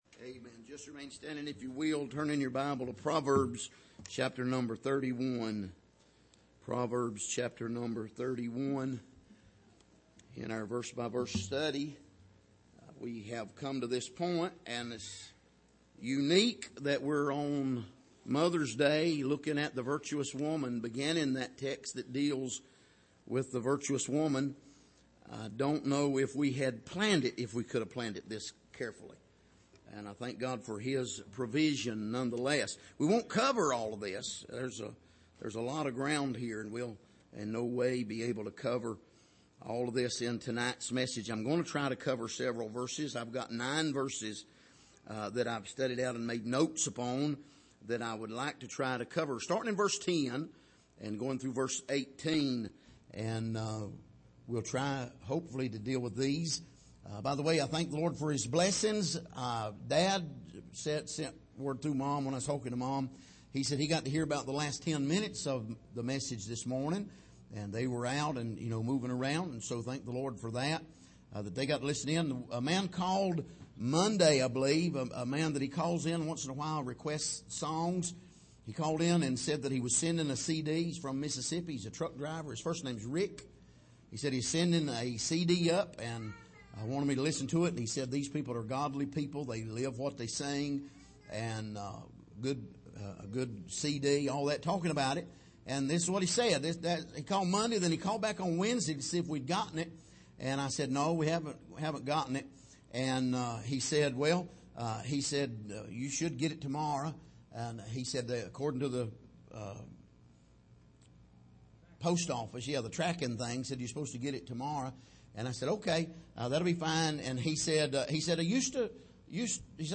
Passage: Proverbs 31:10-18 Service: Sunday Evening